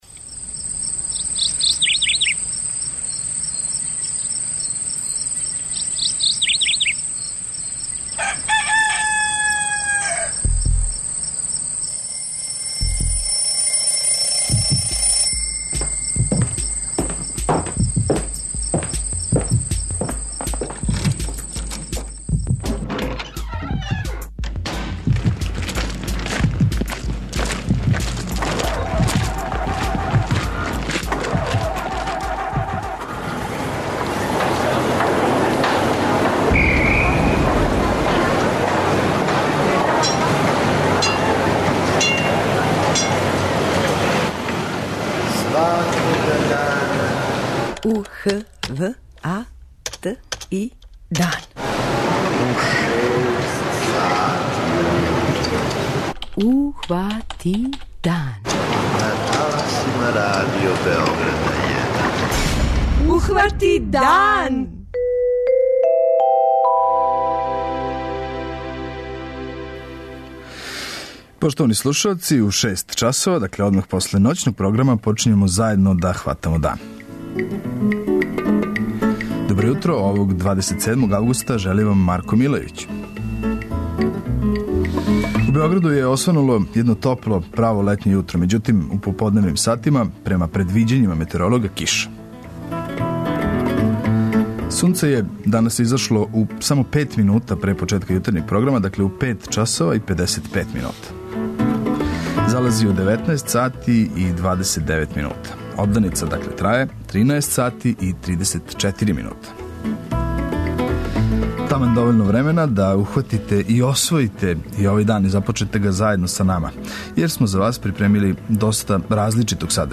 У данашњем издању јутарњег програма говоримо: